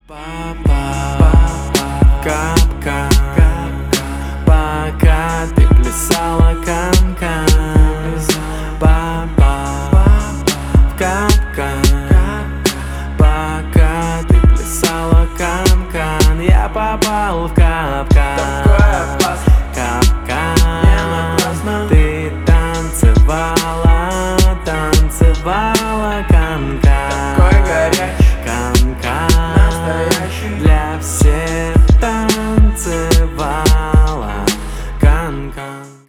поп
рэп